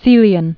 (sēlē-ən)